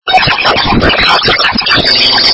какаято девка чето поет на испанском или мексиканском )))